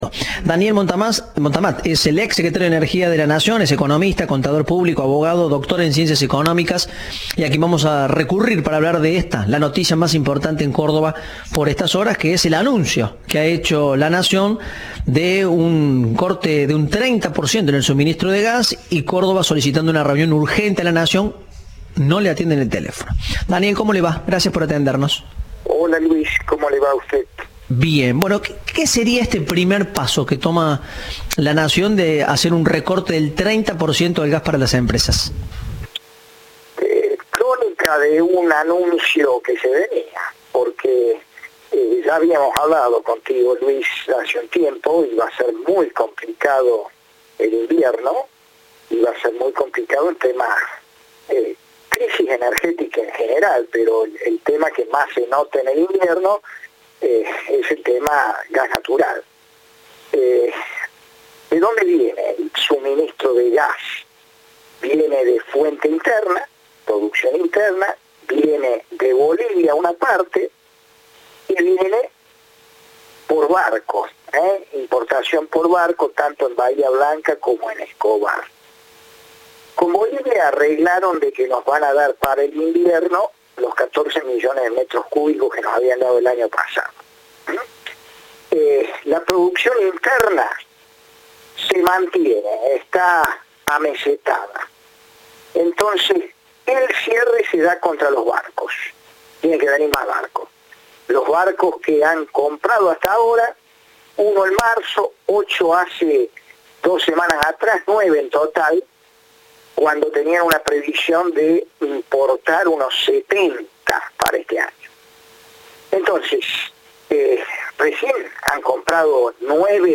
El ex secretario de Energía habló con Cadena 3 sobre la decisión del Gobierno nacional de recortar la provisión de gas natural a empresas locales.
Entrevista de "Informados, al regreso".